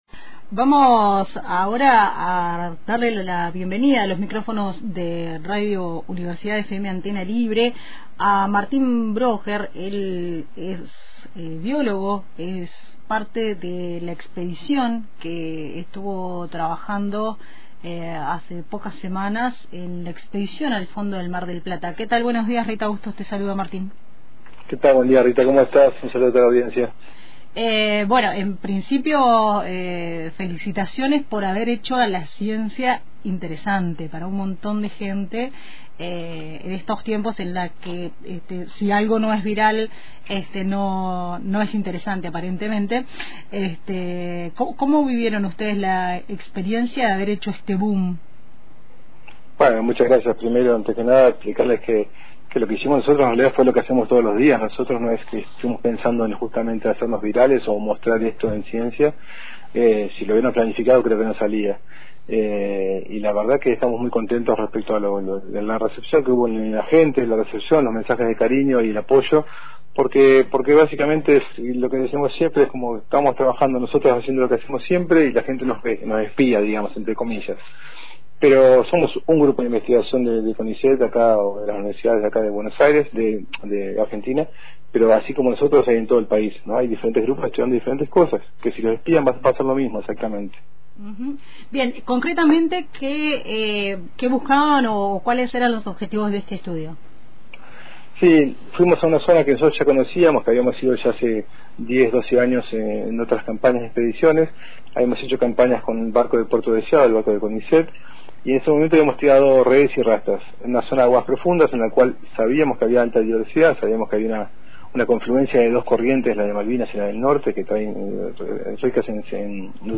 En radio Antena Libre